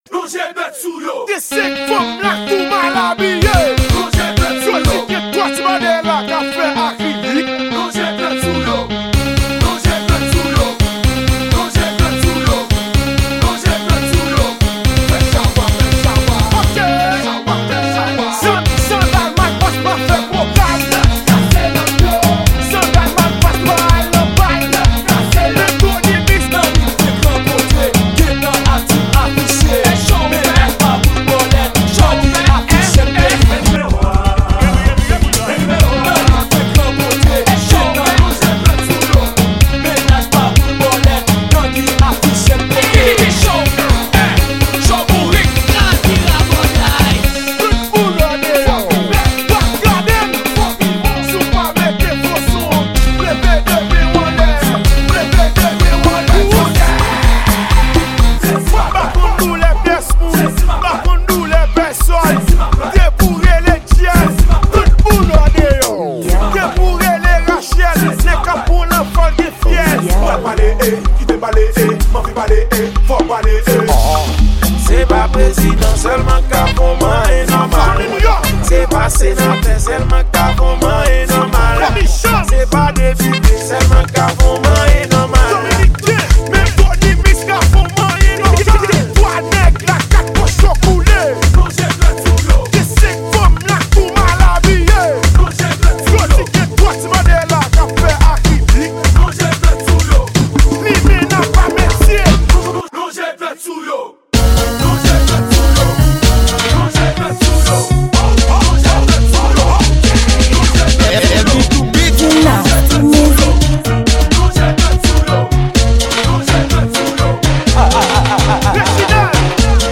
Genre: Remix.